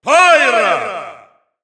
Announcer pronouncing Pyra's name in Russian.
Pyra_Russian_Announcer_SSBU.wav